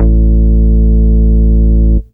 bseTTE48007moog-A.wav